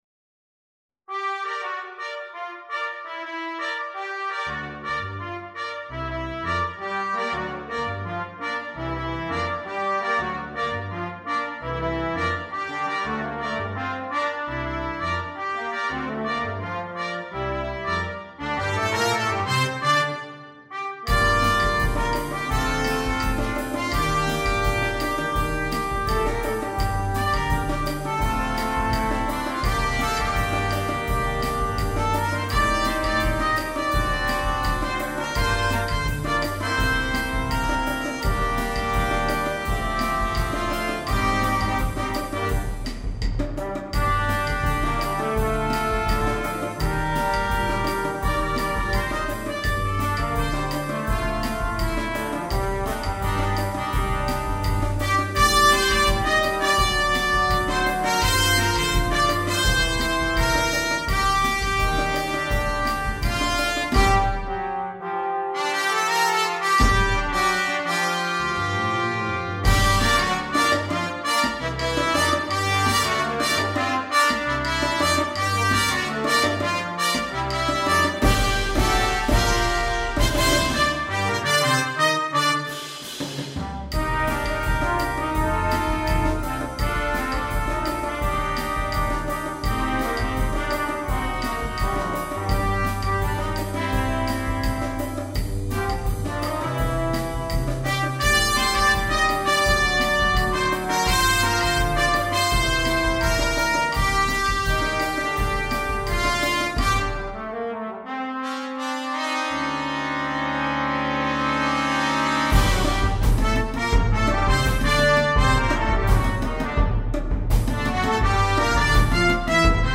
Brass Quintet (optional Drum Set)